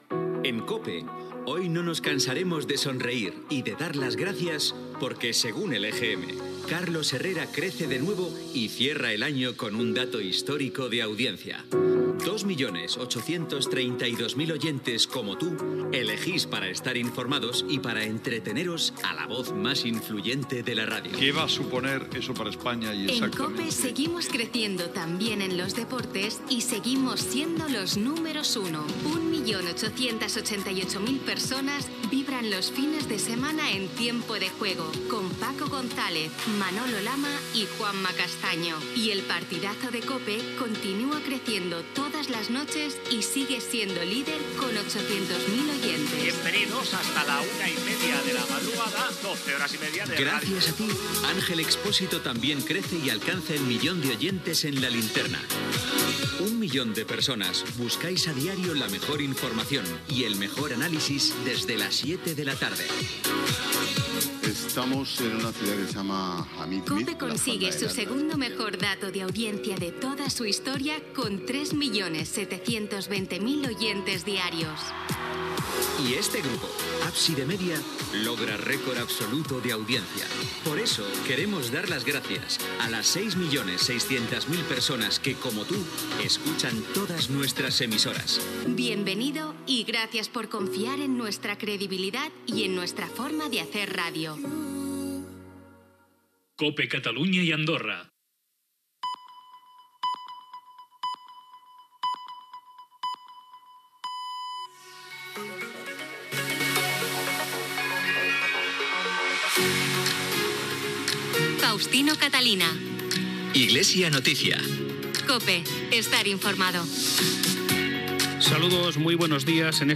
Dades d'audiència de la Cadena COPE, indicatiu de l'emissora, senyals horaris, careta del programa, data, presentació, titulars informatius, indicatiu del programa, celebració de la setmana d'oració per a la unitat dels cristians
Religió